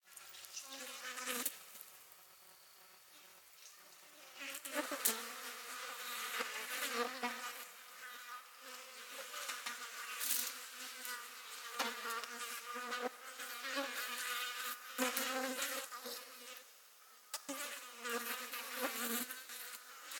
ca37fcf28b Divergent / mods / Soundscape Overhaul / gamedata / sounds / ambient / soundscape / insects / insectday_12.ogg 174 KiB (Stored with Git LFS) Raw History
insectday_12.ogg